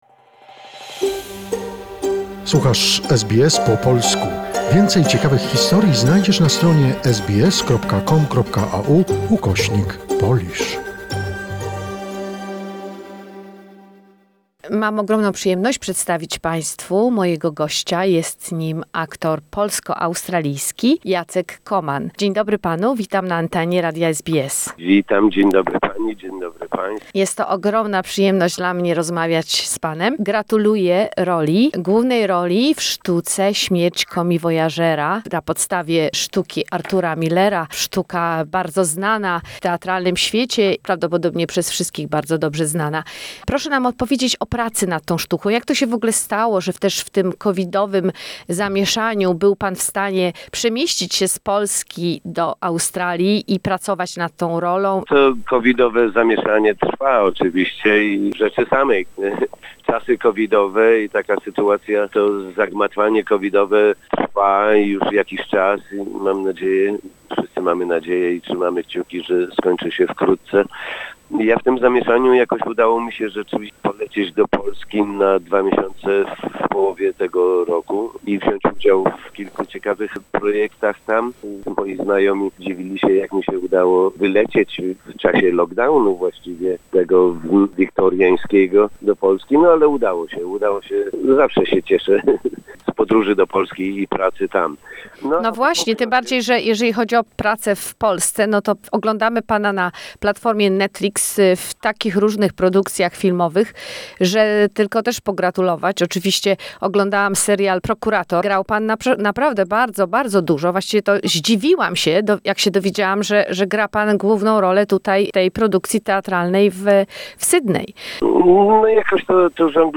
Jacek Koman- is a Polish actor with an electrifying, strong voice.